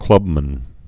(klŭbmən, -măn)